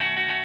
RAP GUITAR.wav